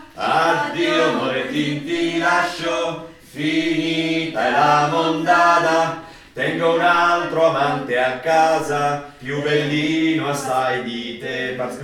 Addio_Morettin_Contralti.mp3